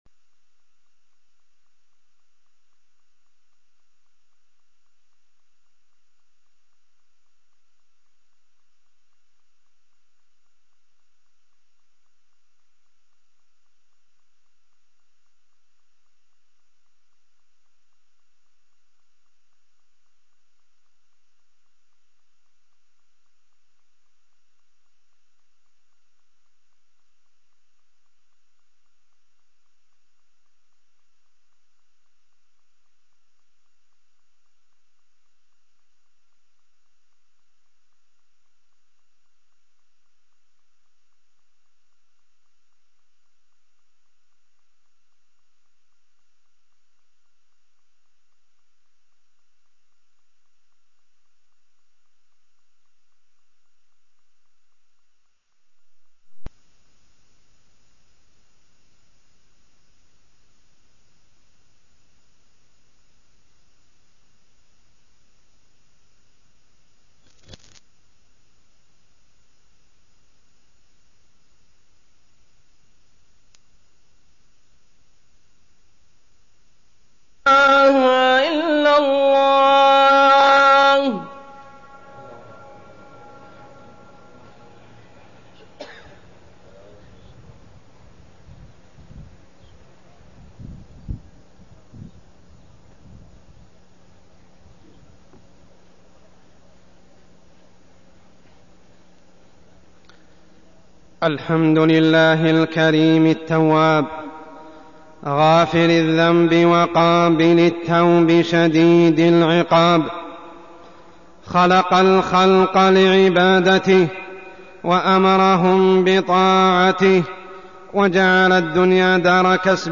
تاريخ النشر ٢٤ شعبان ١٤١٧ هـ المكان: المسجد الحرام الشيخ: عمر السبيل عمر السبيل التوبة النصوح